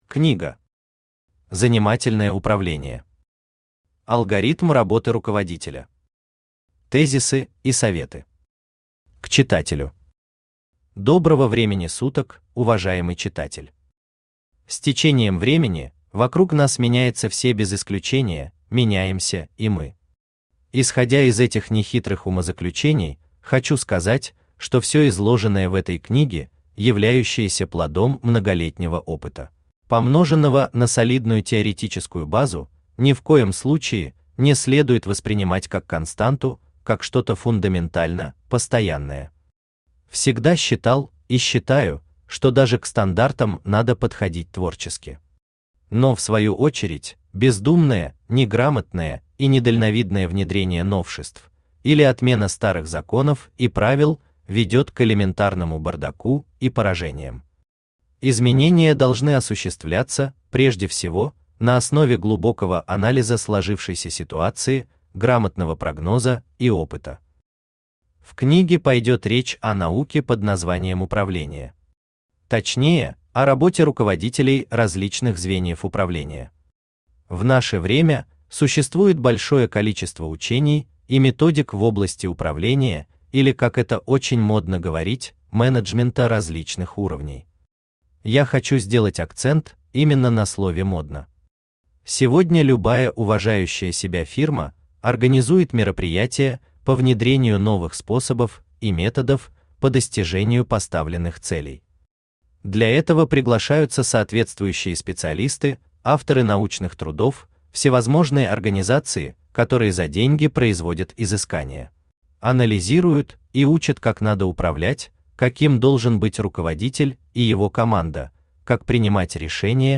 Аудиокнига Занимательное управление. Алгоритм работы руководителя. Тезисы и советы | Библиотека аудиокниг
Тезисы и советы Автор Виктор Владимирович Беник Читает аудиокнигу Авточтец ЛитРес.